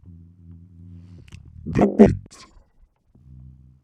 Vocal chop Free sound effects and audio clips
• demonic techno voice "the beat".wav
Changing the pitch and transient for a studio recorded voice (recorded with Steinberg ST66), to sound demonic/robotic.